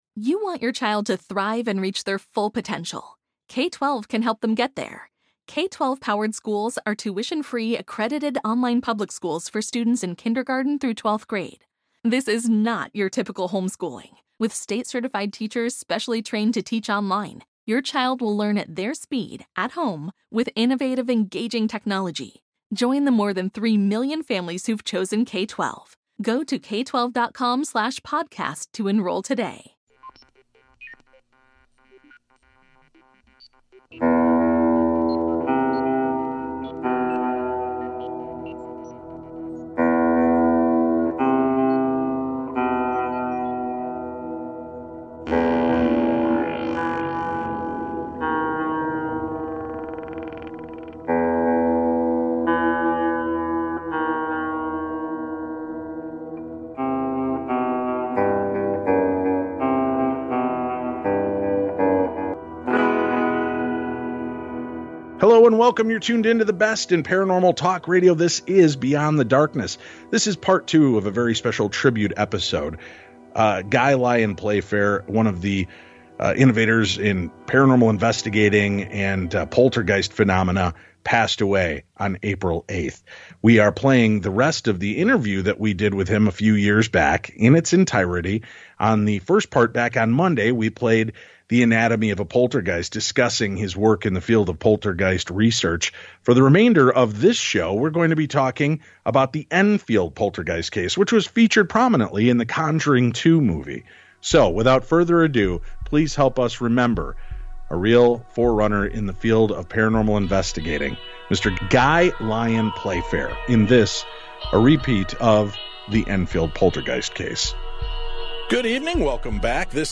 0:53.7 We are playing the rest of the interview that we did with him a few years back in its 0:57.9 entirety.